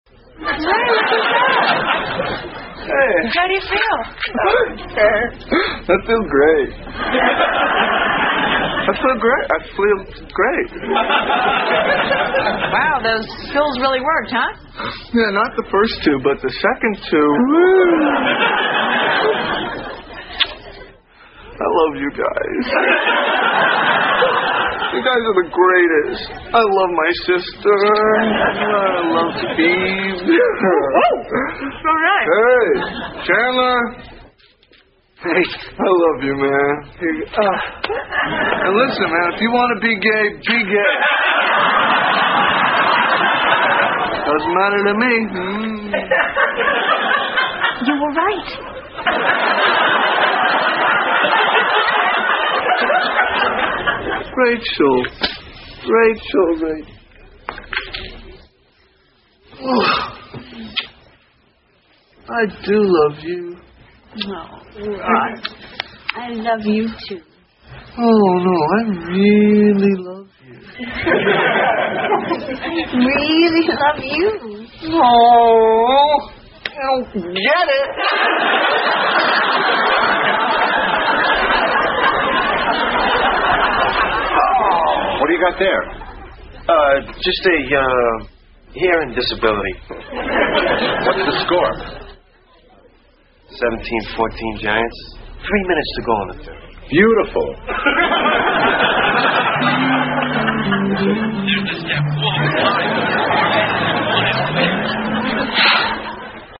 在线英语听力室老友记精校版第1季 第96期:祖母死了两回(10)的听力文件下载, 《老友记精校版》是美国乃至全世界最受欢迎的情景喜剧，一共拍摄了10季，以其幽默的对白和与现实生活的贴近吸引了无数的观众，精校版栏目搭配高音质音频与同步双语字幕，是练习提升英语听力水平，积累英语知识的好帮手。